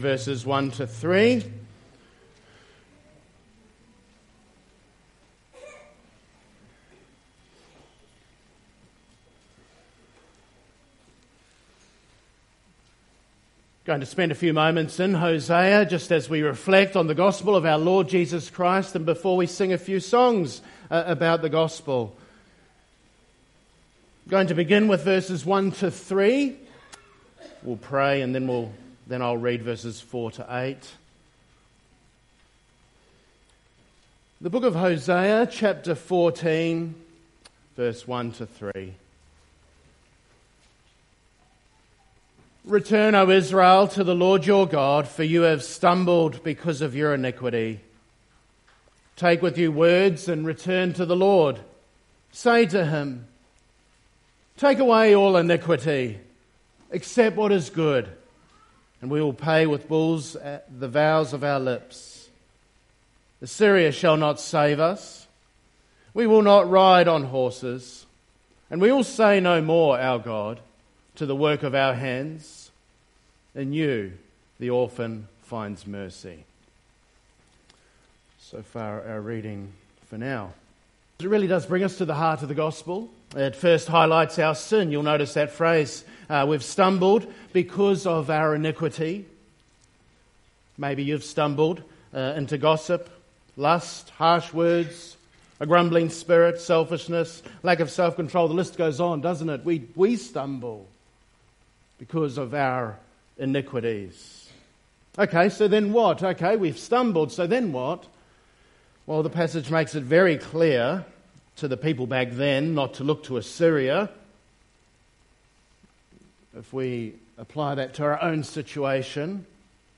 Close Log In using Email Jul 20, 2025 Christ’s Love For The Church MP3 SUBSCRIBE on iTunes(Podcast) Notes 60th Anniversary Service Morning Service - 20th July 2025 Hosea 14:1-8 Revelation 19:6-10 Ephesians 5:18-33